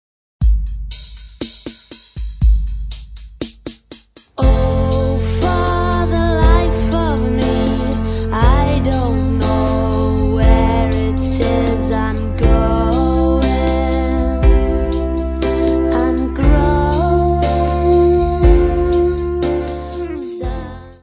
Área:  Pop / Rock